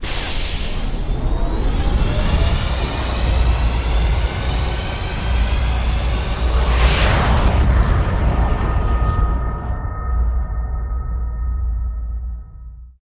Cosmic Rage / ships / Movement / launch5.wav
launch5.wav